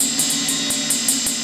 Ride 12.wav